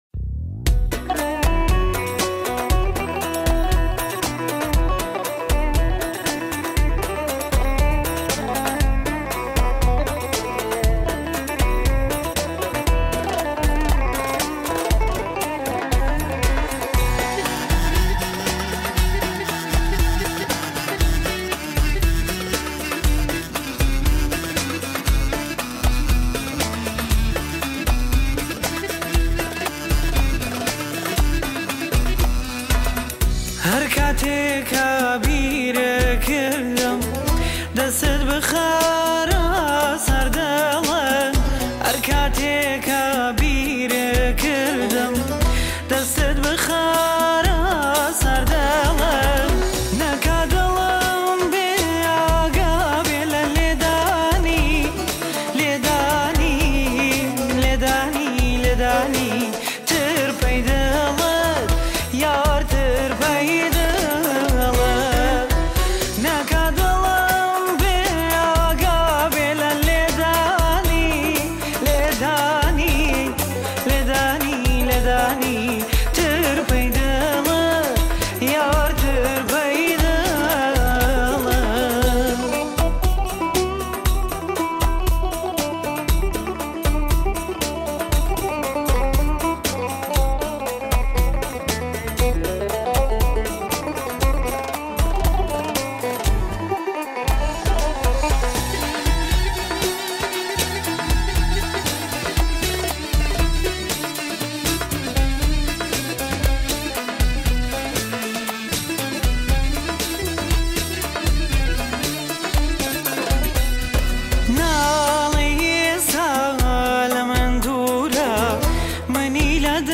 دانلود اهنگ کردی